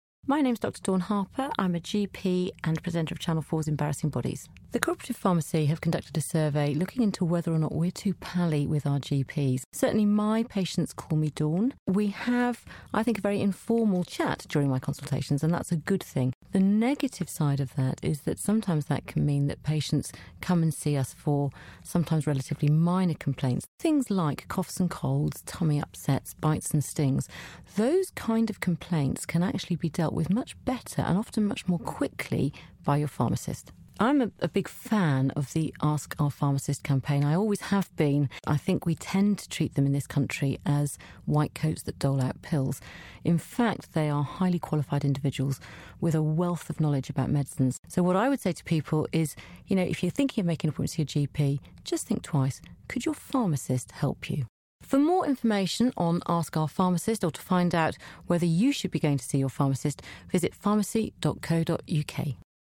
Your pharmacist can be your 1st stop for immediate help without you visiting your local surgery. DR Dawn Harper joined us in HOAX studios to discuss the new research by Co-Operative Pharmacy which coincides with The Ask Our Pharmacist Campaign.